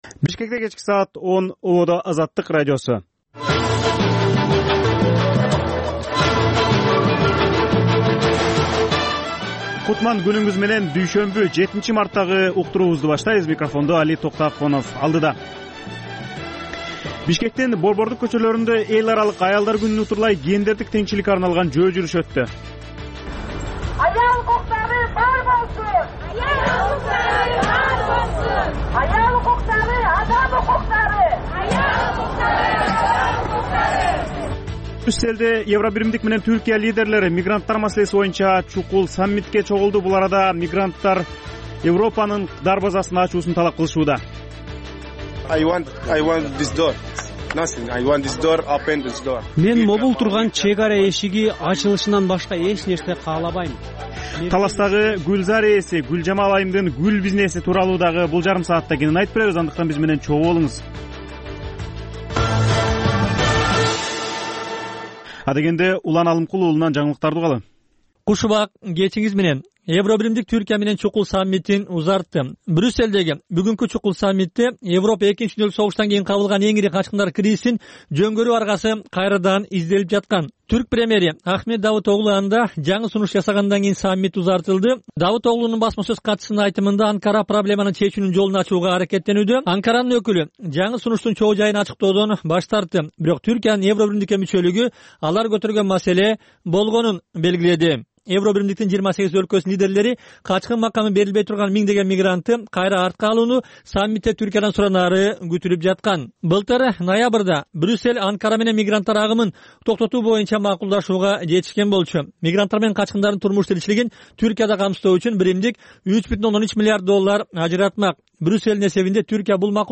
Кечки 10догу кабарлар